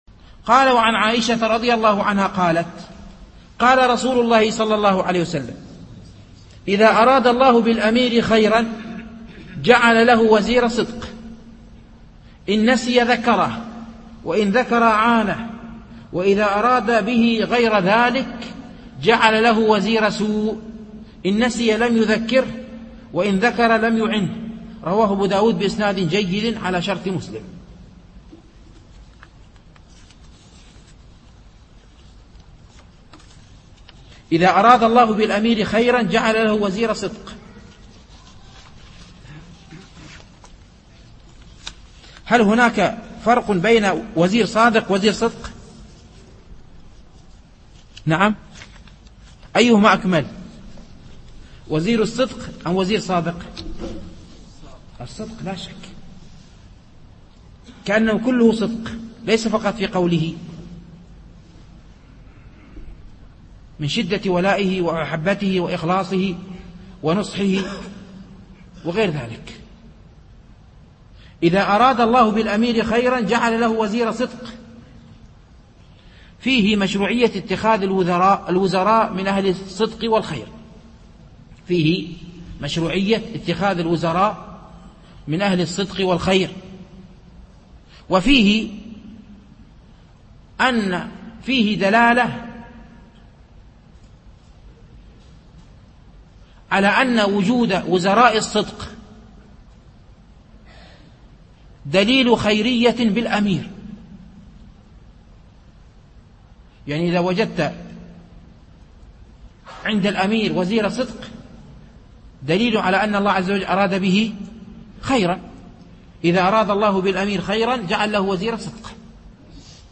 شرح حديث (إذا أراد الله بالأمير خيرا جعل له وزير صدق , إن نسي ذكره ، وإن ذكر أعانه , وإذا أراد به غير ذلك جعل له وزير سوء...)